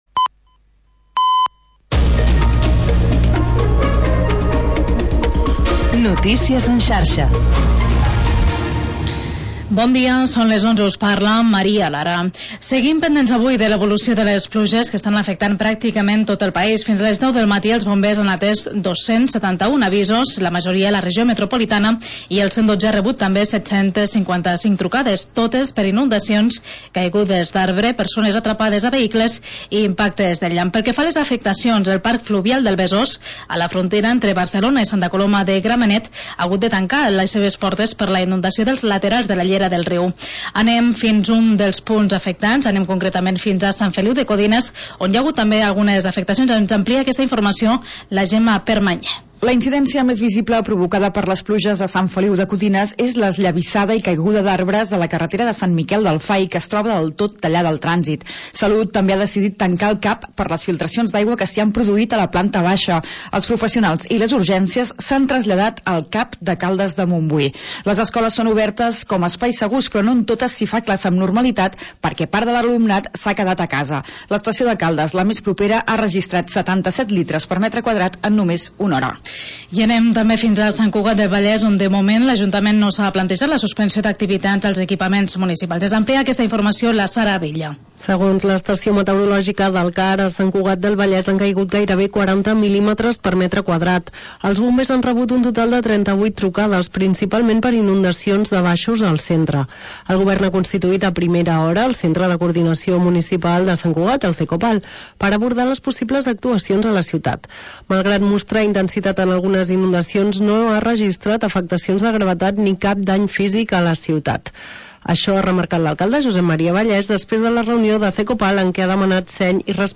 Magazín d'entreteniment per encarar el dia